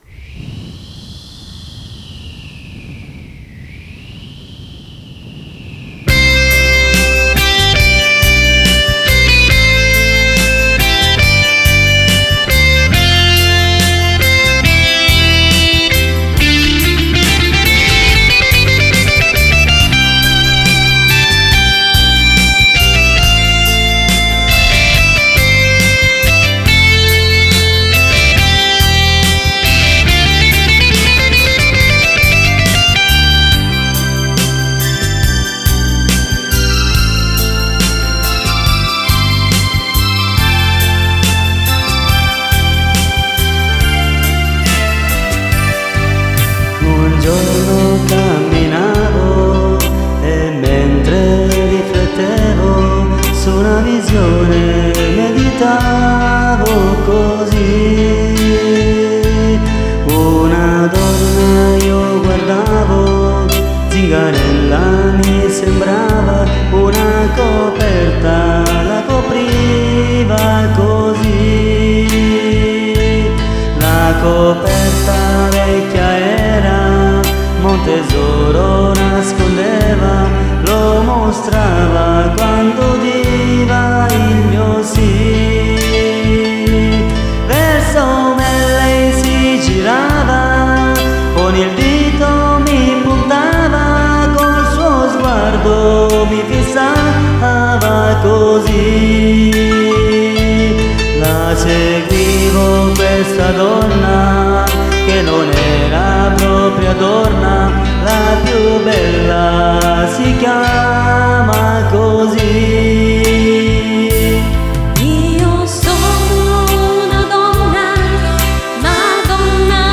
note di chitarra